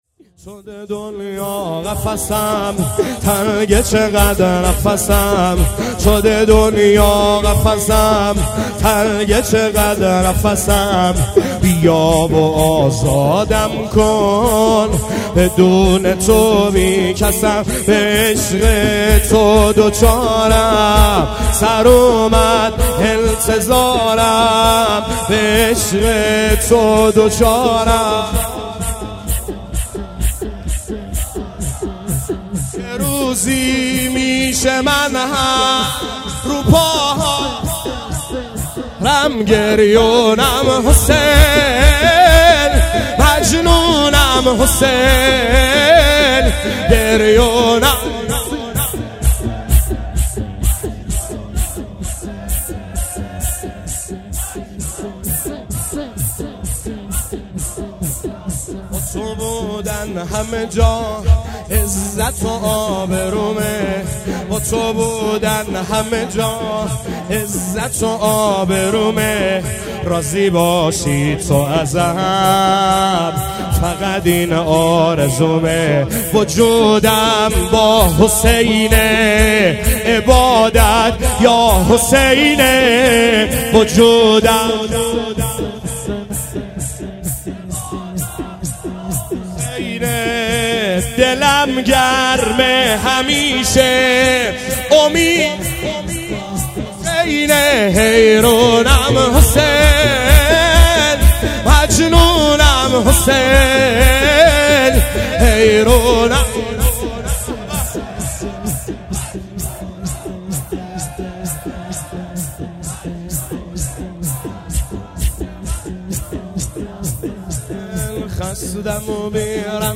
شور | شده دنیا قفسم
گزارش صوتی شب سوم محرم 98 | هیأت محبان حضرت زهرا سلام الله علیها زاهدان